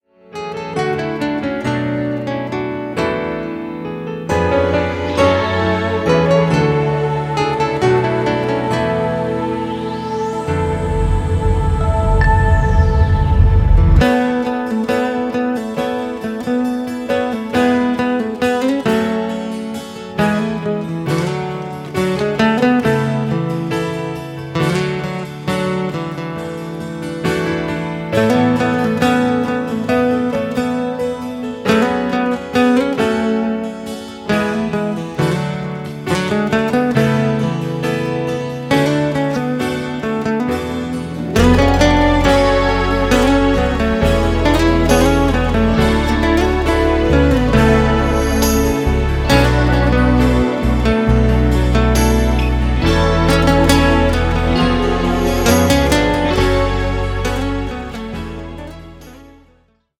Instrumental
Dabei greift er nicht nur auf die E-Gitarre zurück
akustischen Gitarre